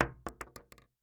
46265b6fcc Divergent / mods / Bullet Shell Sounds / gamedata / sounds / bullet_shells / shotgun_wood_5.ogg 23 KiB (Stored with Git LFS) Raw History
shotgun_wood_5.ogg